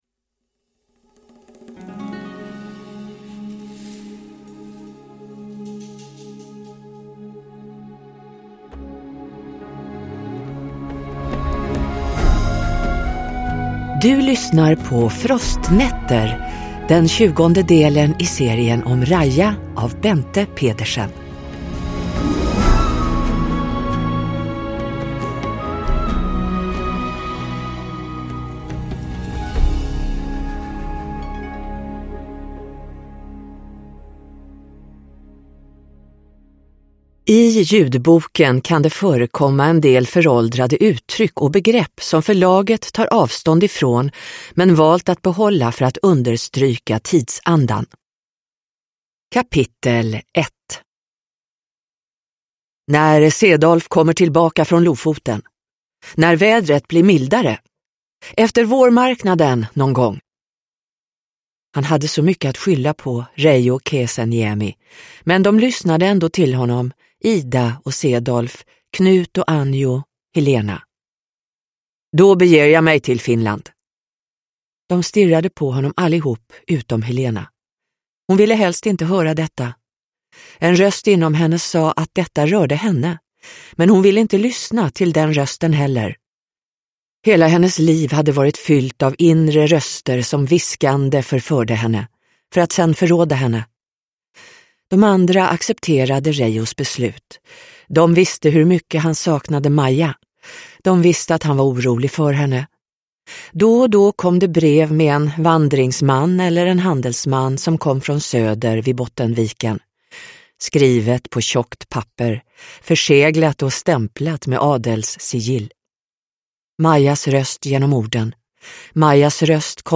Frostnätter – Ljudbok – Laddas ner